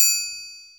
OZ-Perc (Hate).wav